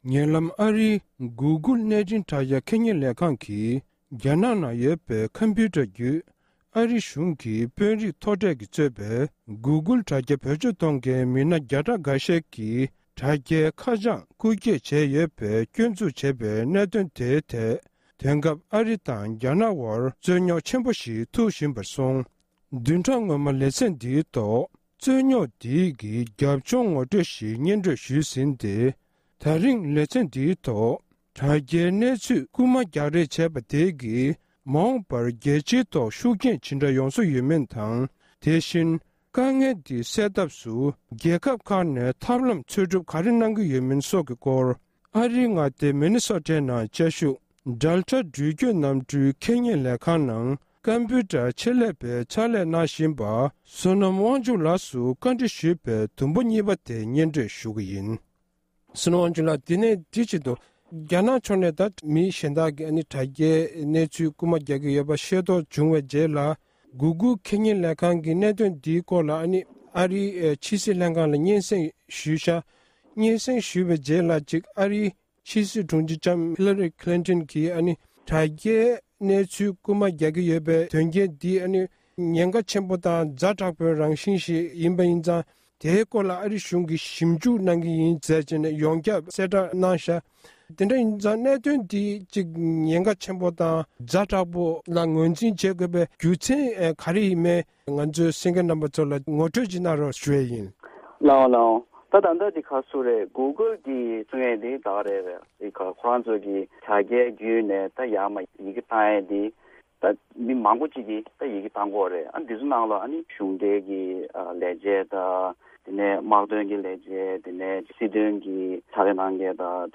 མུ་མཐུད་གནས་འདྲི་ཞུས་ཡོད།།